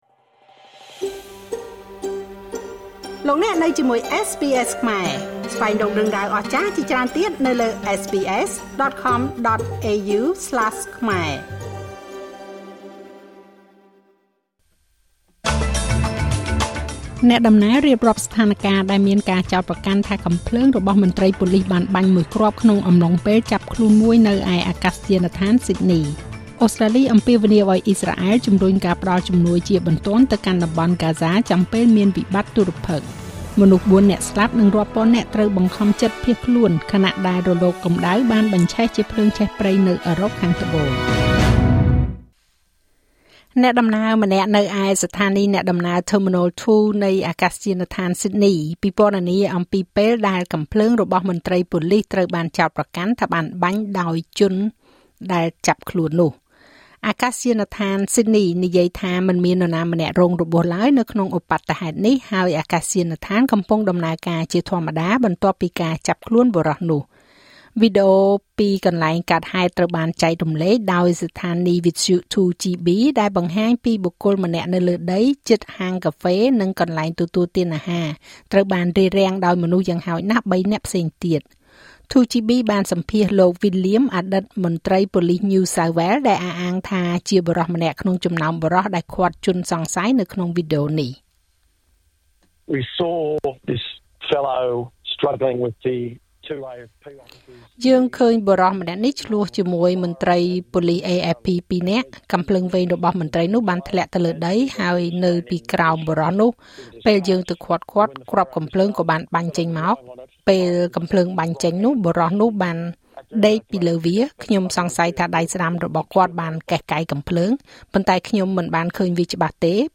នាទីព័ត៌មានរបស់SBSខ្មែរ សម្រាប់ថ្ងៃពុធ ទី១៣ ខែសីហា ឆ្នាំ២០២៥